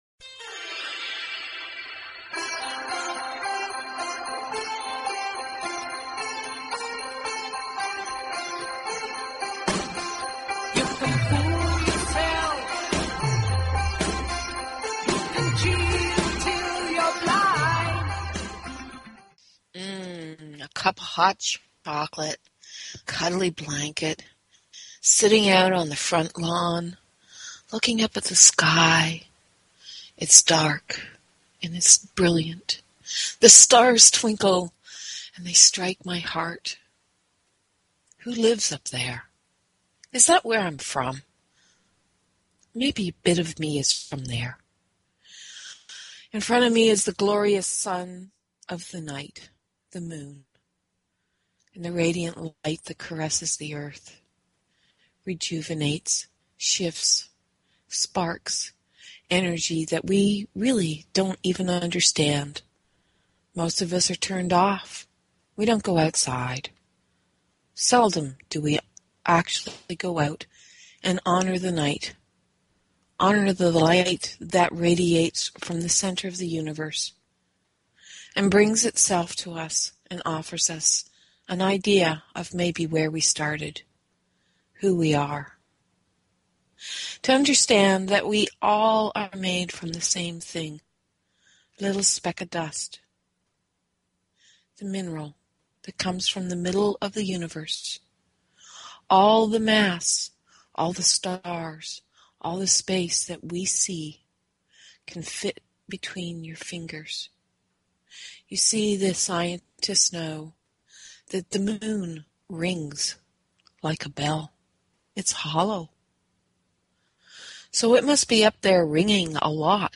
Talk Show Episode, Audio Podcast, eSO_Logic_Radio and Courtesy of BBS Radio on , show guests , about , categorized as